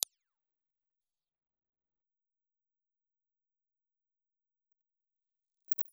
Dynamic
Cardioid
Speech (male) recorded with the Vitavox B50 talkback microphone.
Moving coil. Response 60 Hz-8 kHz.